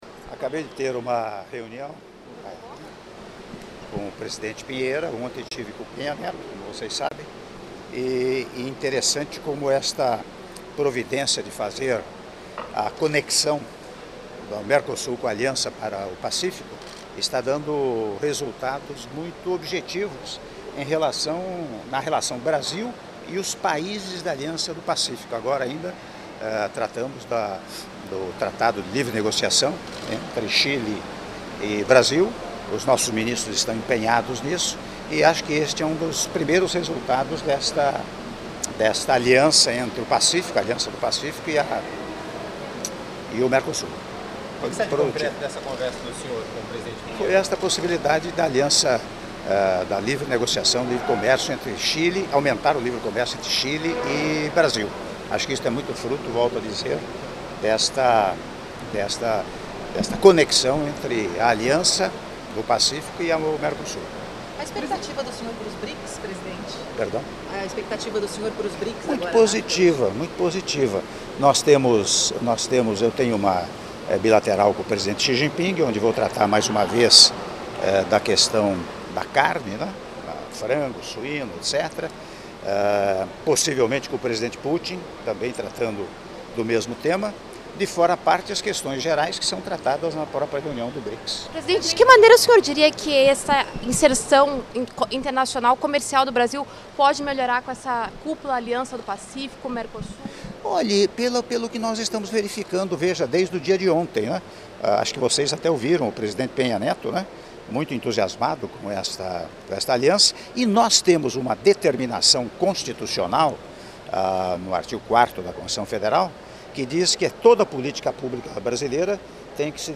Áudio da Entrevista coletiva concedida pelo Presidente da República, Michel Temer, após reunião bilateral com o Presidente da República do Chile, Sebastián Piñera - Puerto Vallarta/México (03min33s)